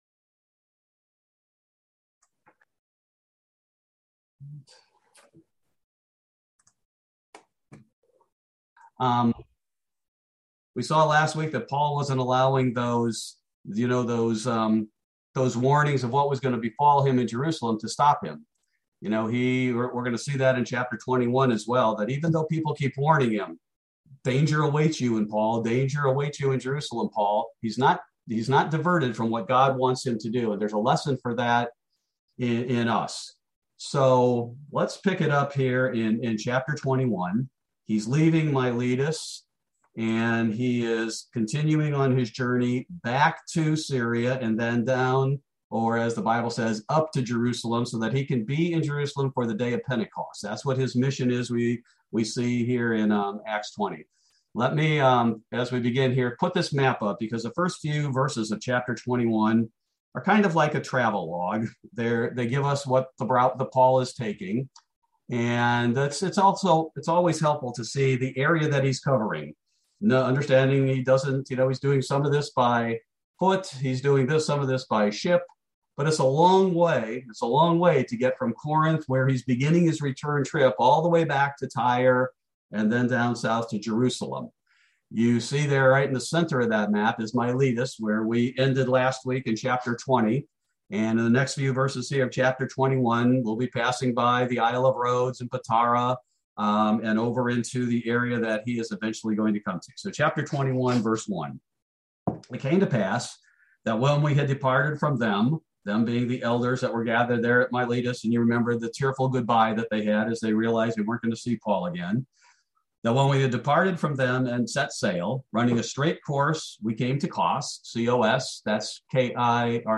Bible Study: December 22, 2021